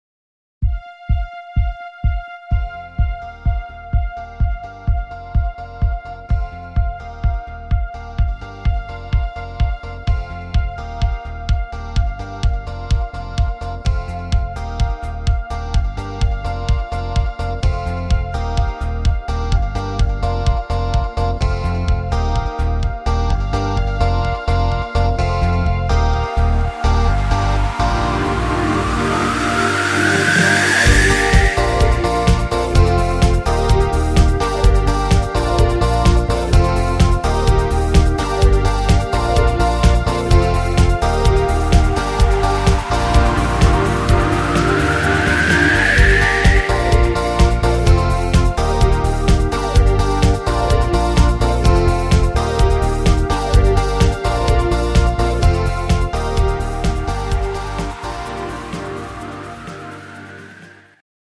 Rhythmus  Dancefloor
Art  Englisch, Pop